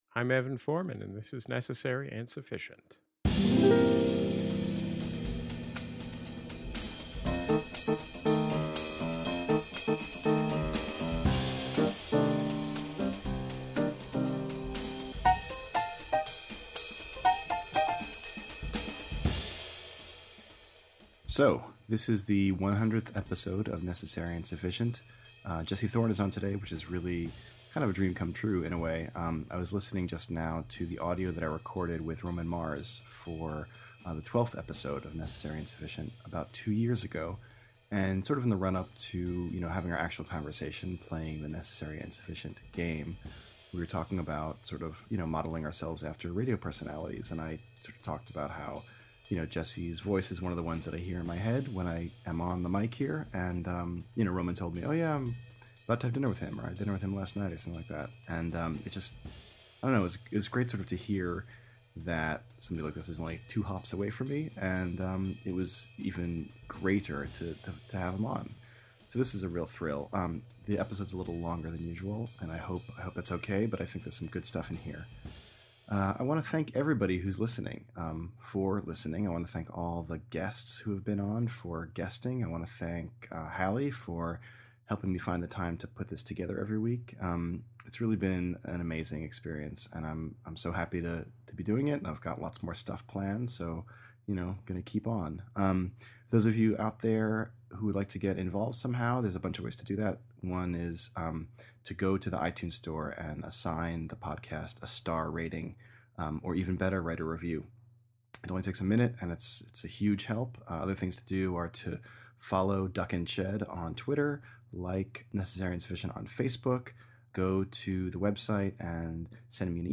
They then open the envelope live on the air. When I appeared on episode 100 of the show, the words were “fashion” and “style.” A discussion followed.